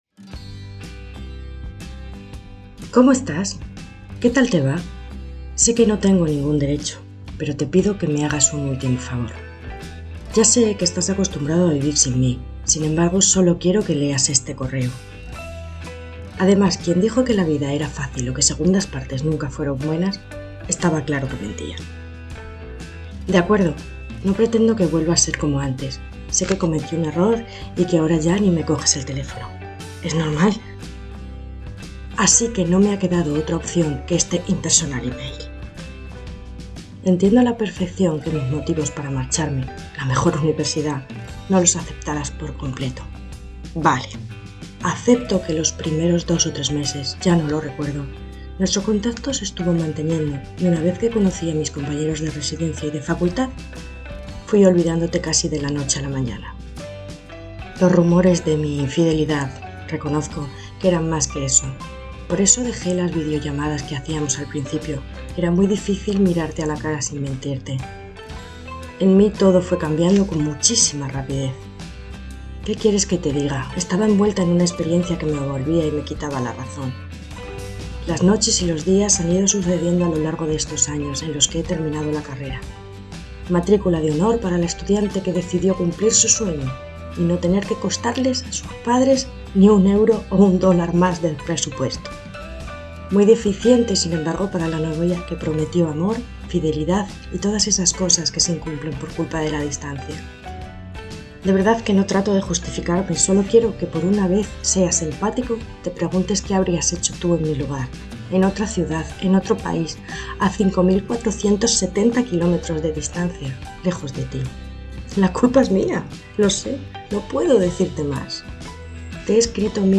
te-he-echado-de-menos-musica-y-voz.mp3